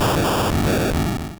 Cri de Voltorbe dans Pokémon Rouge et Bleu.